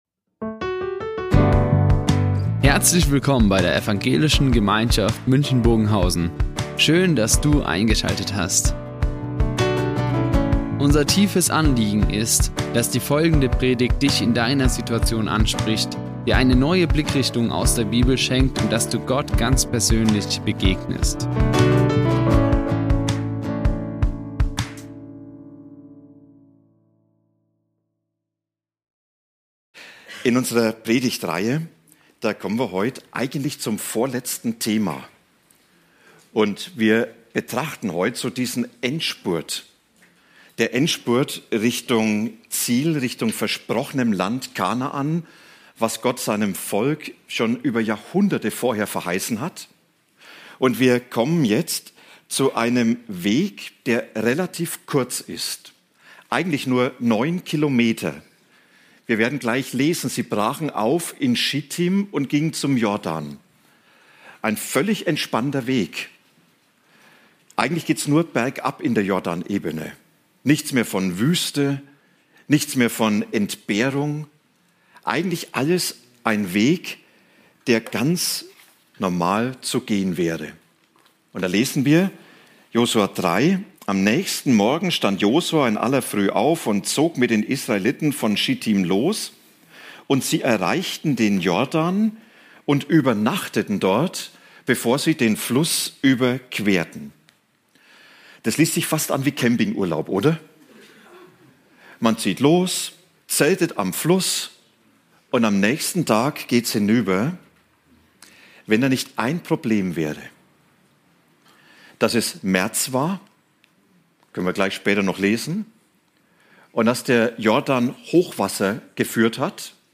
Wichtige Denkmäler für die Zukunft | Predigt Josua 2-3 ~ Ev. Gemeinschaft München Predigten Podcast